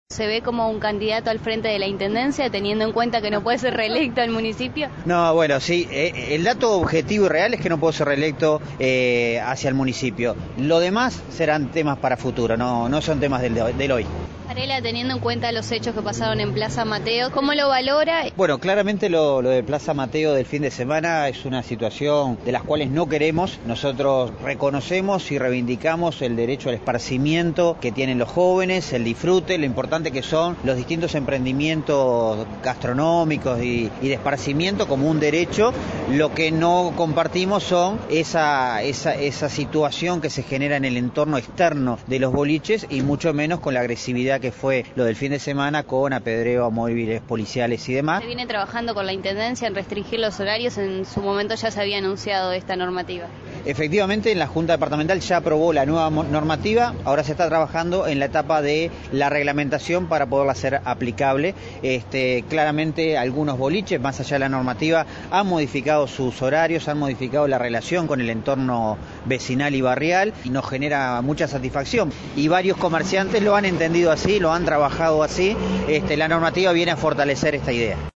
El director del Centro de Gestión de Movilidad, Boris Goloubintseff, dijo que próximamente se anunciarán las nuevas cámaras de control, como también el funcionamiento de los radares que ya están instalados.